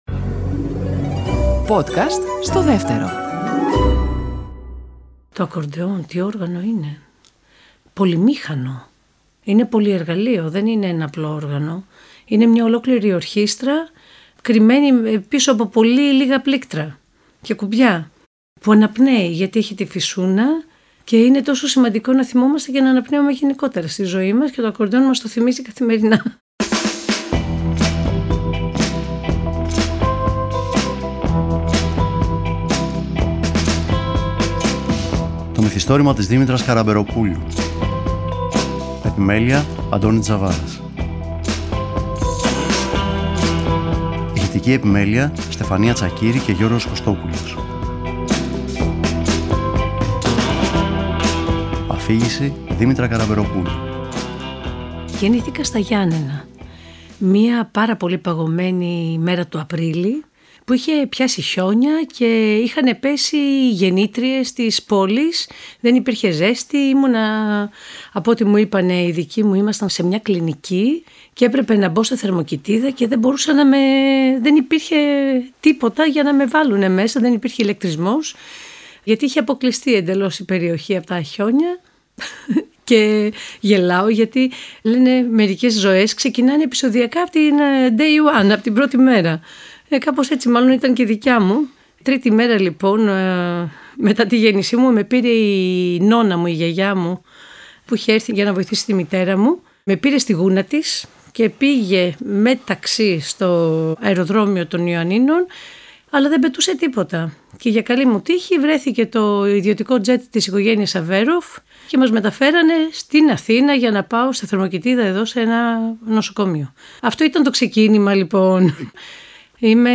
Σε κάποιο διάλειμμα μεταξύ χορωδίας και πρόβας για την παρουσίαση του τρίτου άλμπουμ των Σύννεφων, μπήκε στο στούντιο του Δεύτερου για να αφηγηθεί ιστορίες από το προσωπικό της Μυθιστόρημα, το οποίο μάλιστα ξεκινά με έναν -κυριολεκτικά- μυθιστορηματικό τρόπο: